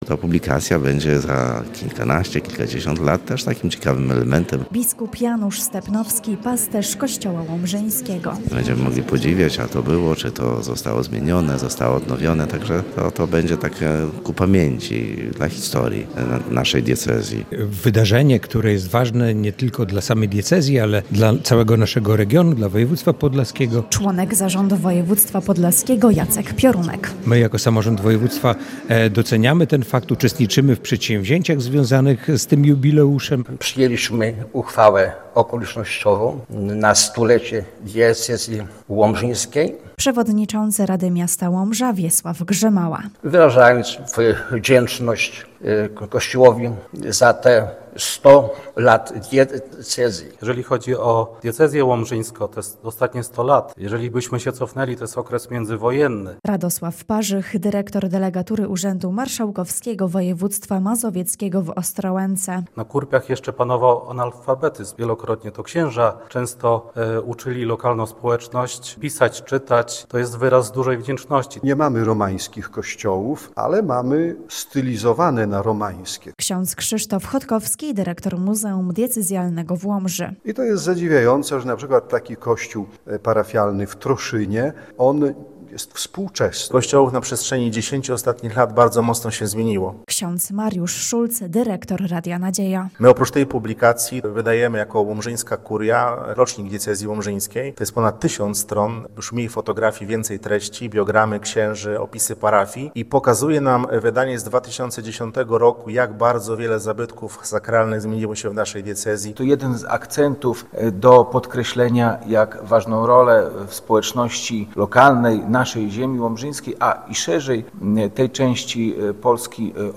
W Muzeum Diecezjalnym w Łomży oficjalnie zaprezentowano w poniedziałkowy (08.12) wieczór album pamiątkowy pt. ,,Kościoły Diecezji Łomżyńskiej”.
Podczas konferencji podkreślano, że album ma nie tylko upamiętnić jubileusz, ale też zachęcić wiernych do odkrywania piękna naszej diecezji. Zapraszamy do wysłuchania relacji z wydarzenia: